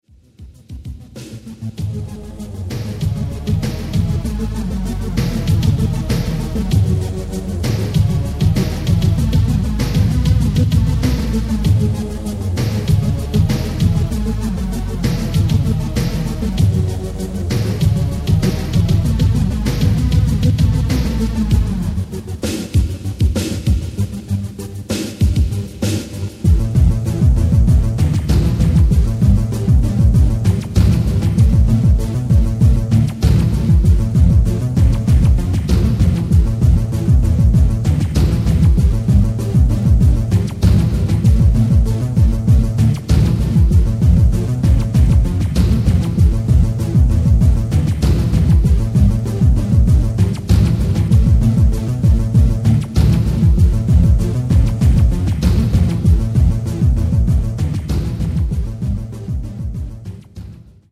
scrolling interface. i used to play bass drum, snare, bass sound and pad with
here are a couple of (very grainy) sound examples of the amiga stuff: